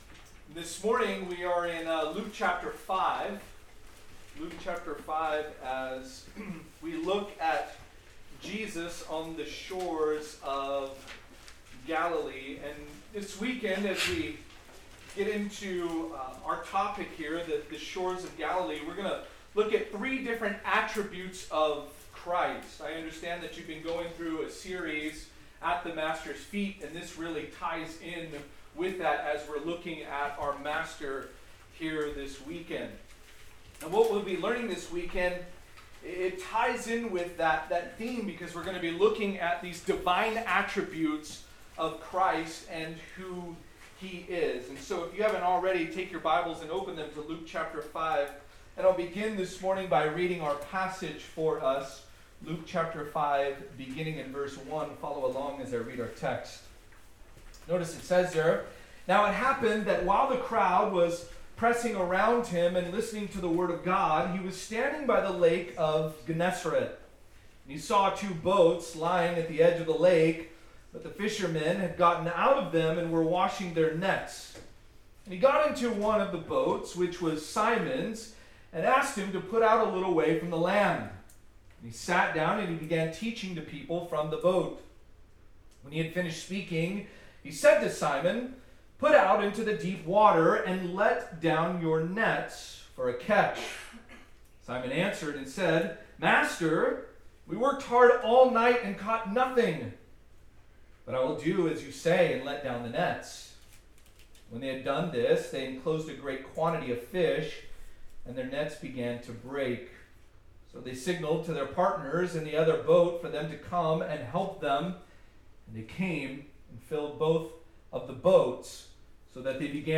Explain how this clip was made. College/Roots Roots Summer Retreat 2025 - On the Shores of Galilee Audio Series List Next ▶ Current 1.